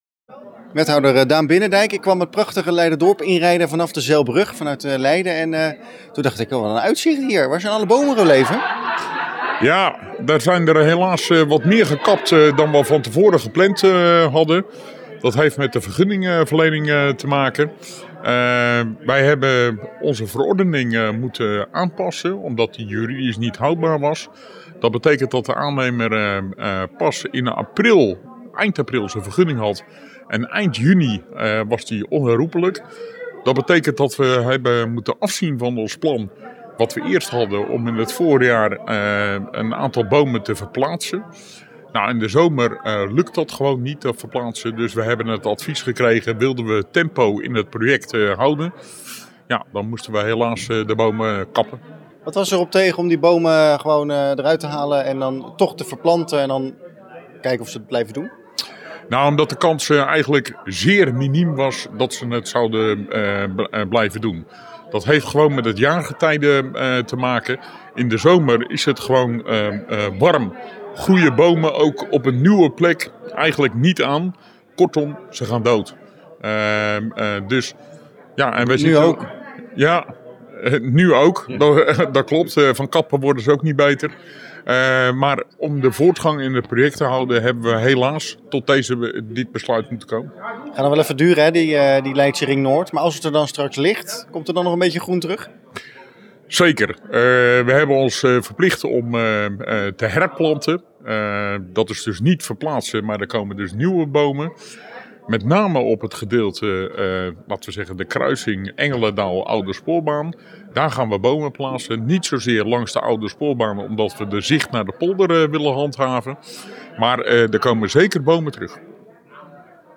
AUDIO: Wethouder Daan Binnendijk over de kap.